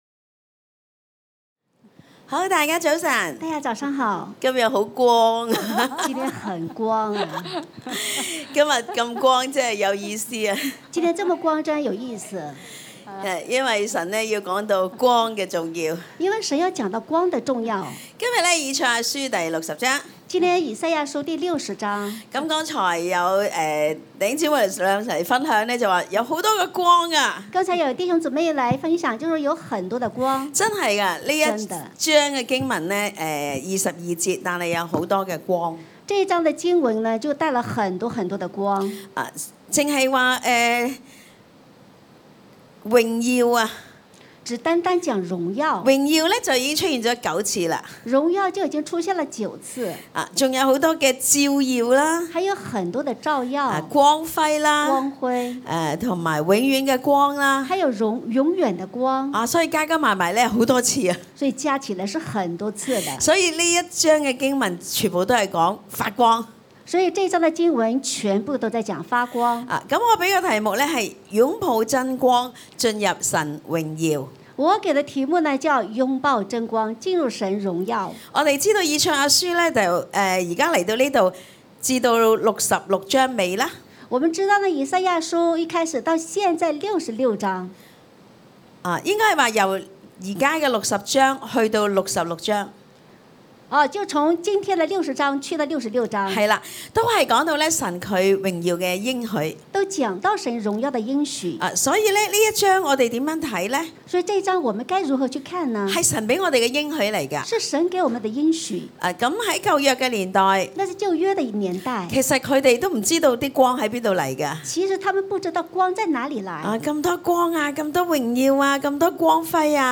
2人分享經歷，生命曾被神的光照明，能悔改回轉。